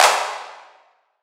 Clap OS 01.wav